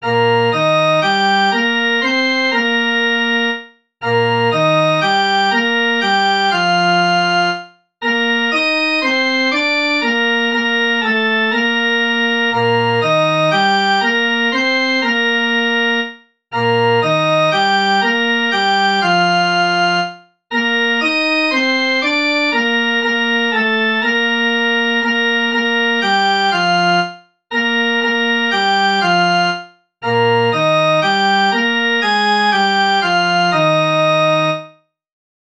Melodie